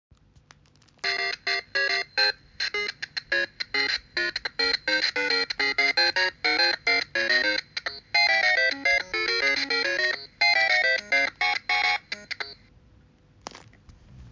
• 🎶 Musikalische Geburtstagsüberraschung